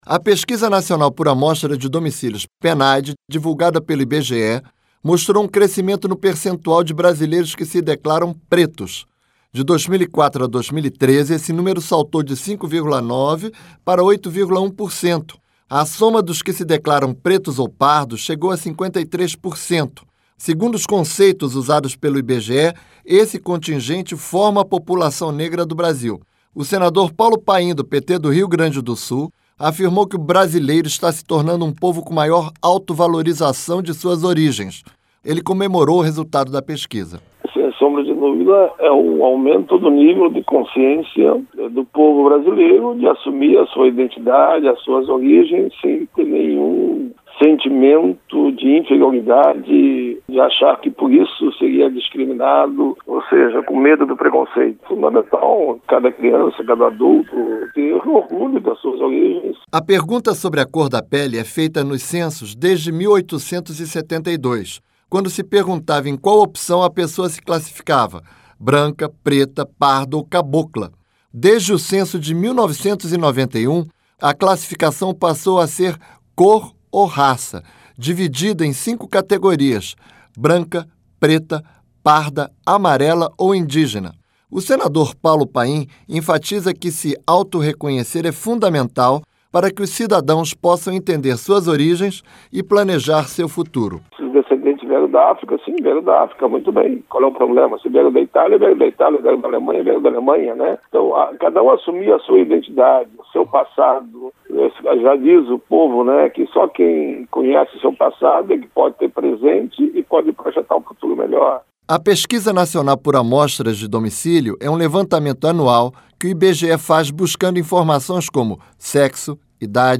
LOC: O SENADOR PAULO PAIM, DO PT DO RIO GRANDE DO SUL, ACREDITA QUE ESTÁ OCORRENDO UMA AUTOAFIRMAÇÃO DE BRASILEIROS QUE ANTES NÃO ASSUMIAM SUA PRÓPRIA COR.